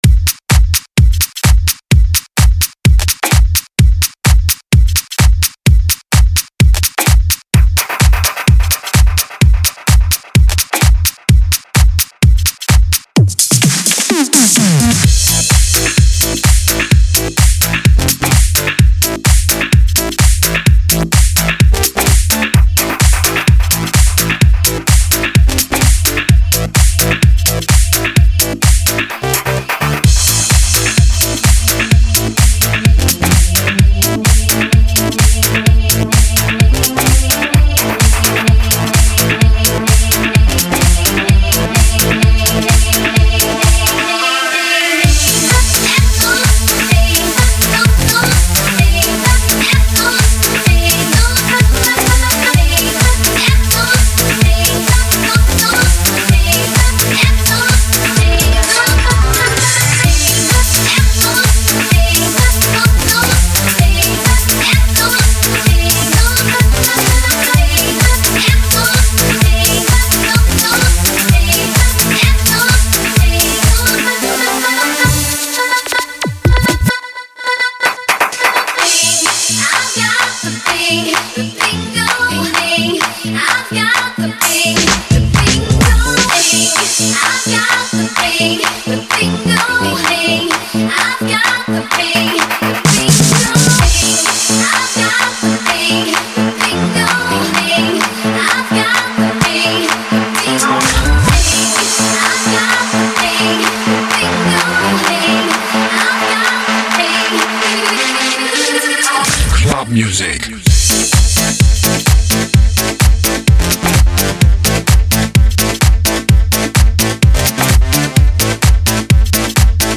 (Ремикс) PR
Качество урезал до 256..так как оригинал 17 Мб.....